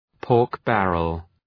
pork-barrel.mp3